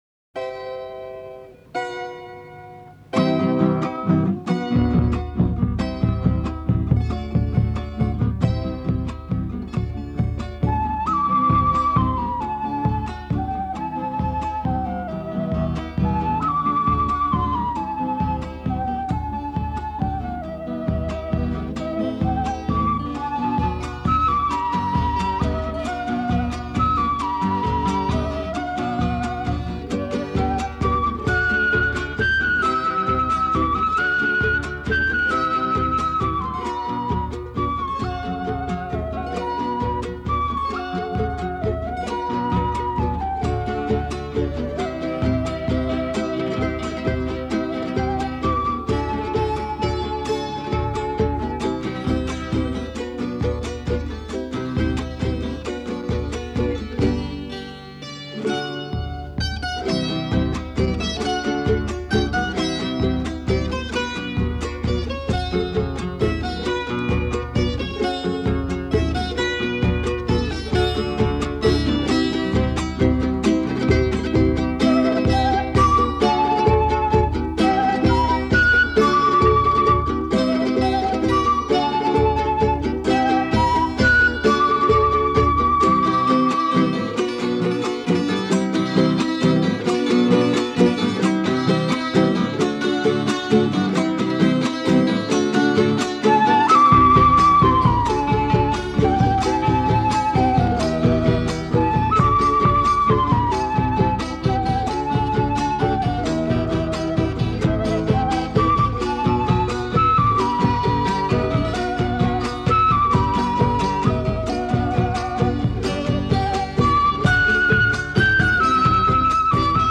ESTILO: Cantautor